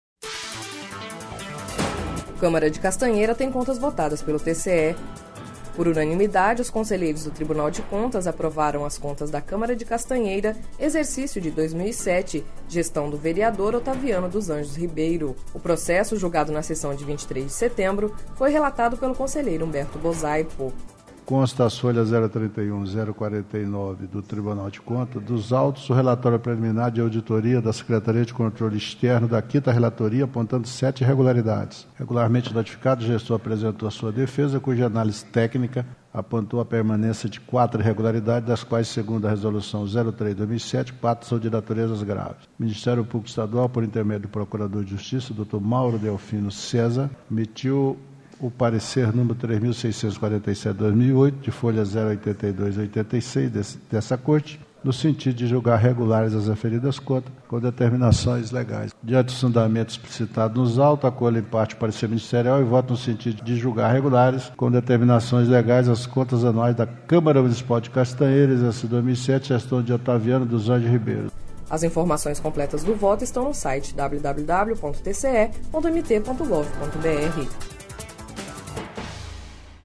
Sonora: Humberto Bosaipo– conselheiro do TCE-MT